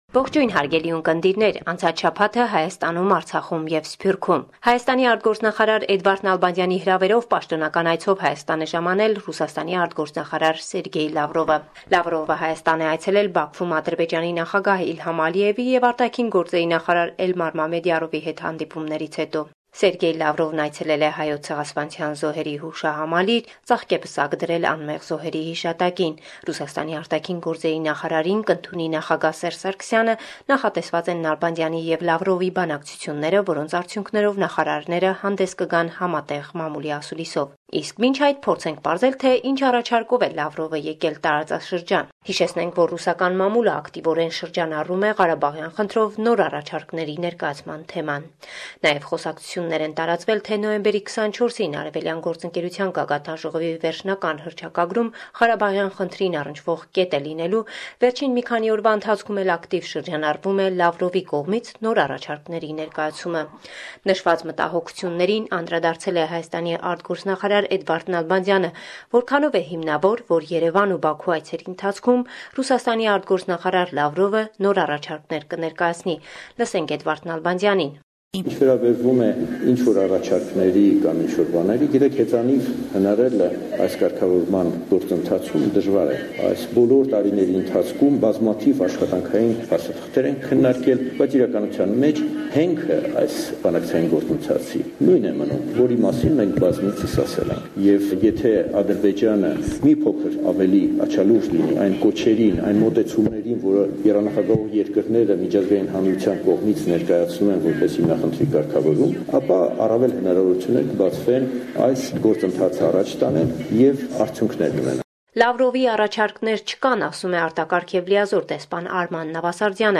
Վերջին Լուրերը – 21 Նոյեմբեր, 2017